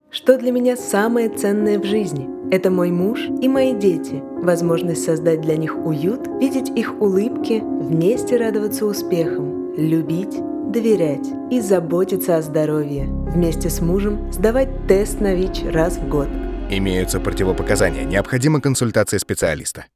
ТЕСТ НА ВИЧ 1 РАЗ В ГОД. ЖЕНСКИЙ ГОЛОС_аудио.wav